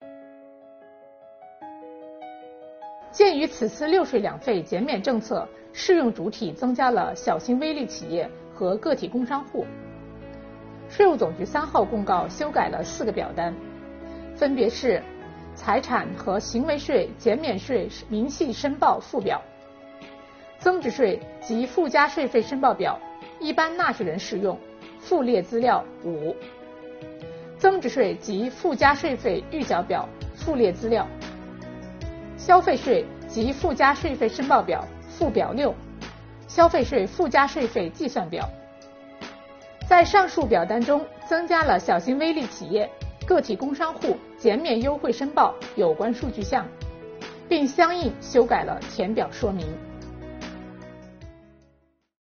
近日，国家税务总局推出新一批“税务讲堂”系列课程，为纳税人缴费人集中解读实施新的组合式税费支持政策。本期课程由国家税务总局财产和行为税司副司长刘宜担任主讲人，解读小微企业“六税两费”减免政策。